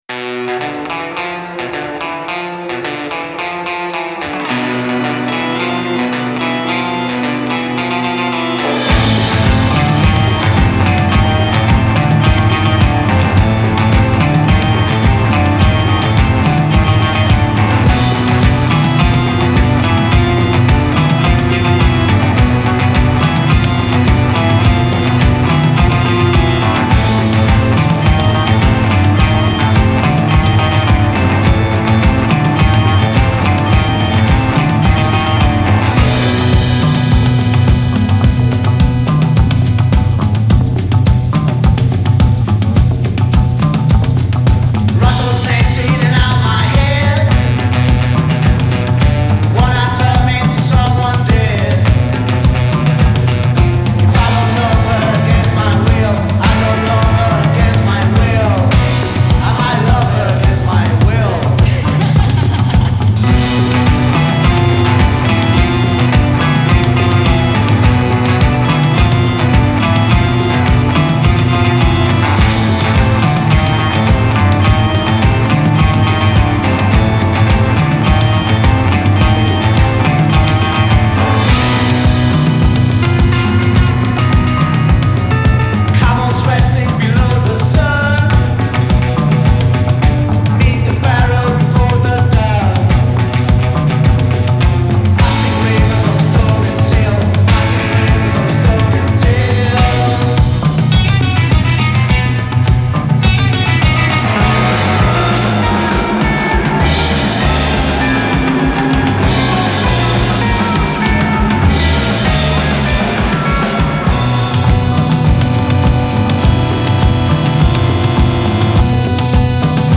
hypnotic grooves
makes you feel like you’re in an old Spaghetti Western film